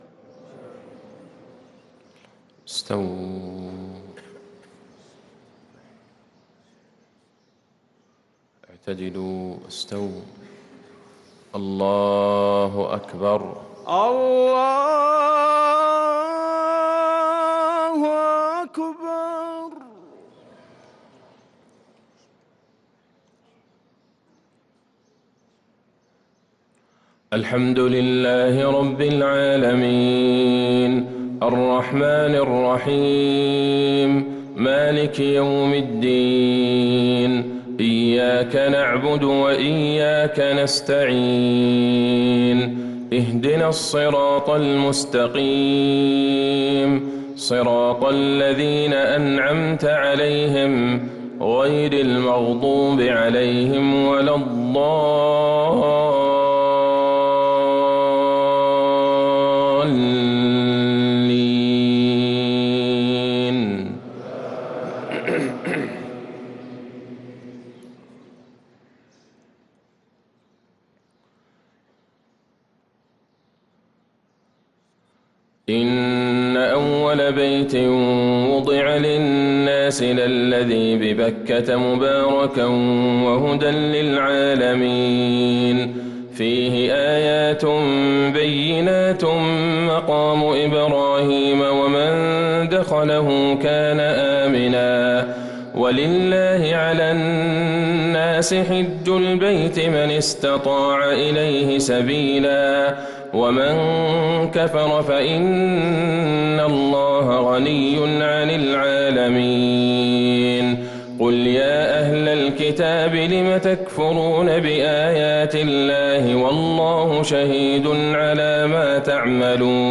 فجر الخميس ٥ صفر ١٤٤٤هـ | سورة آل عمران ٩٦ - ١١٥ | Fajr prayer from Al Imran 1-9-2022 > 1444 🕌 > الفروض - تلاوات الحرمين